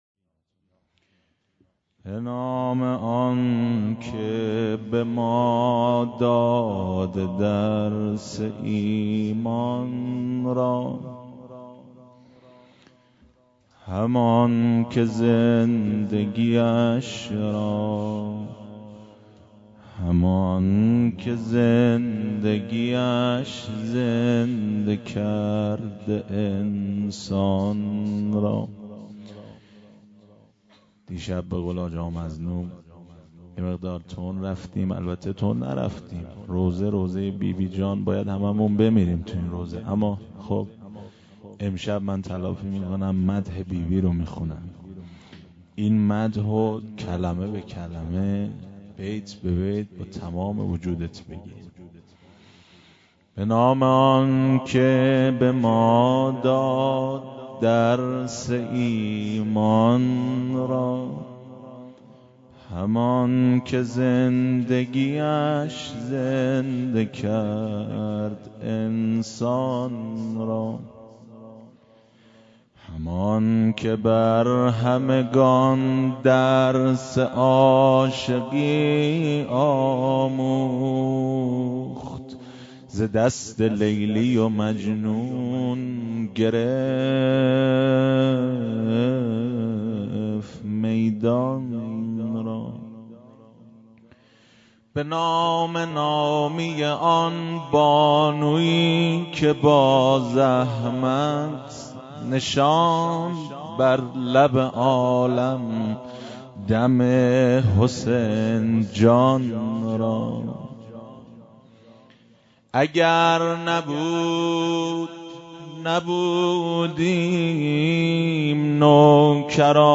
روضه.mp3